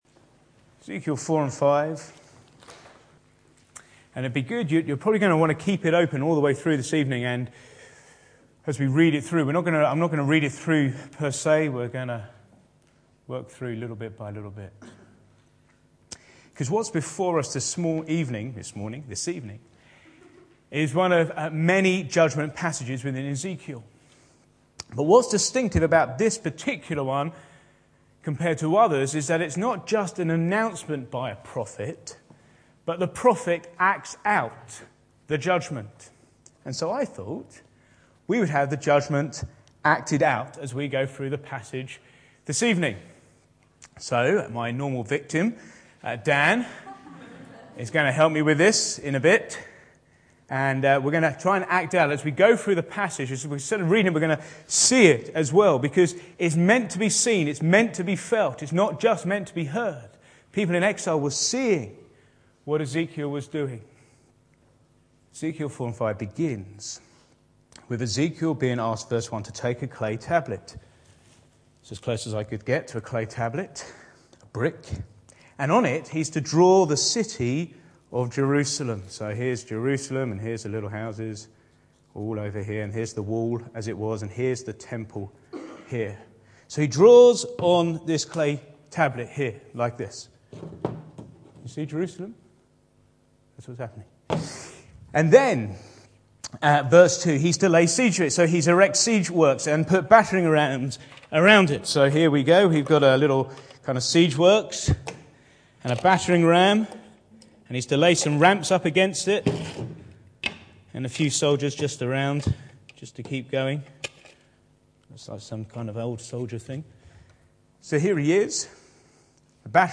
Back to Sermons Acted out Judgement